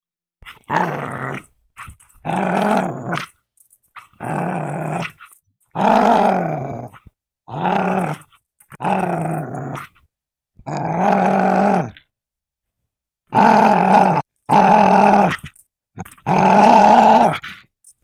Dog Growling
Dog_growling.mp3